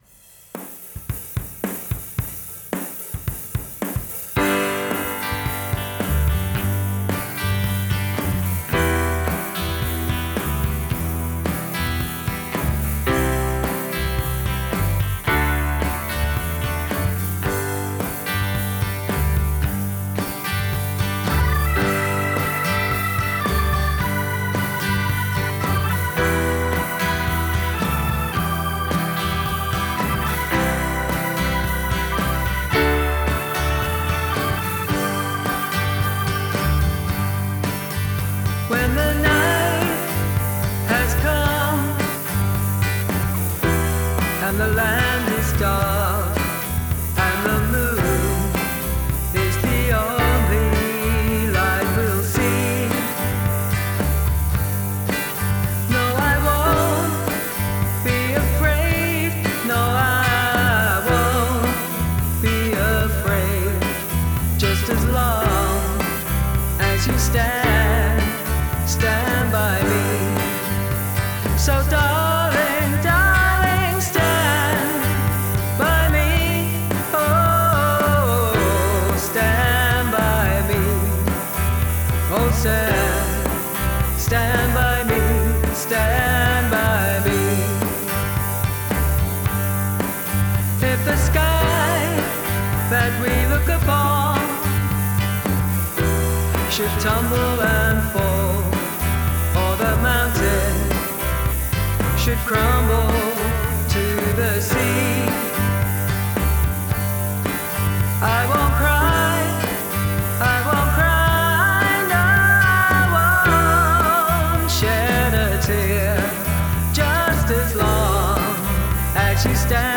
Bass
Gorgeous vocals!